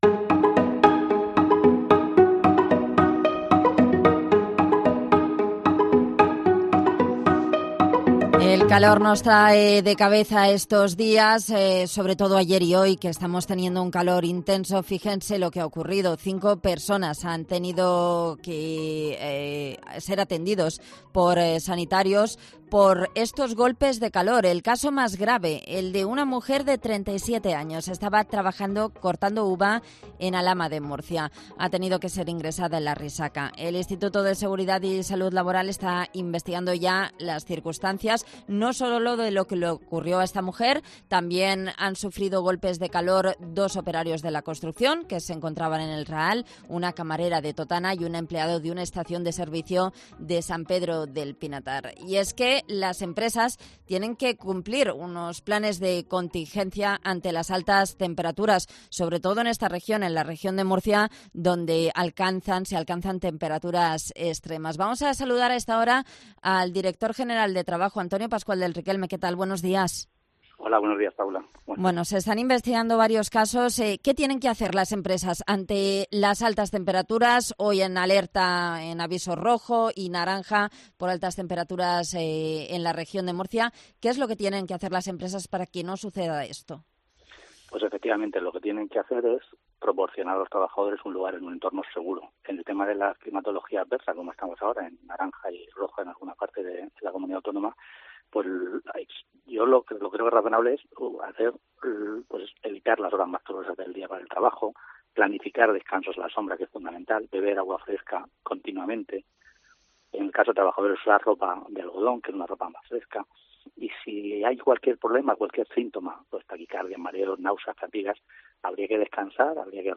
Antonio Pascual del Riquelme, director general de Trabajo
Así lo ha contado en COPE Murcia el director general de Trabajo, Antonio Pascual del Riquelme